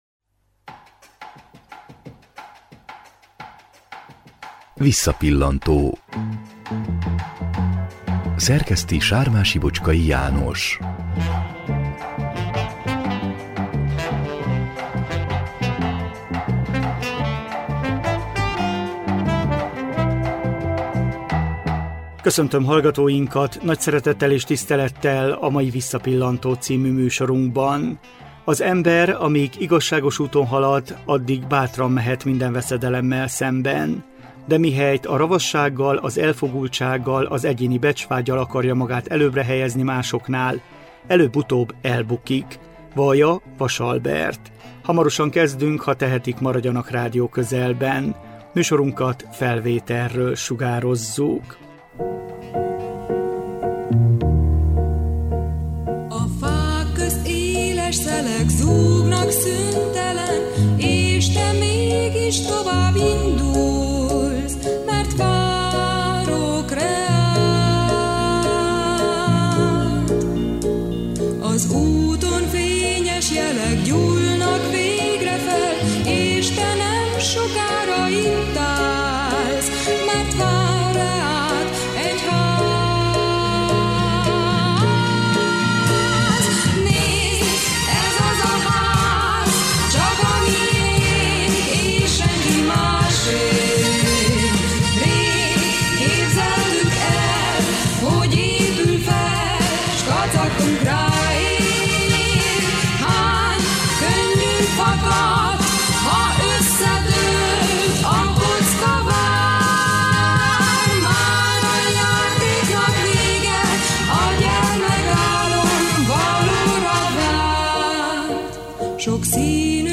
2010 októberében készült Vele zenés beszélgetés.